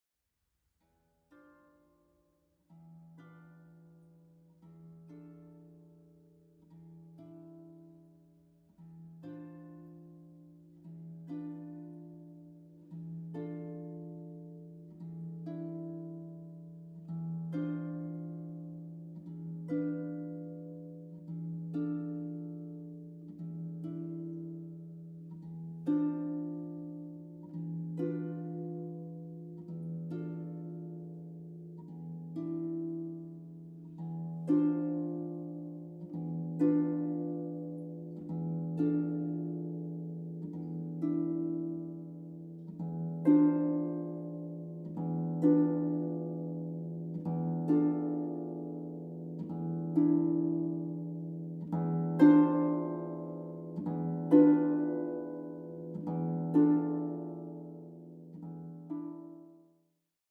Zeitgenössische Musik für Harfe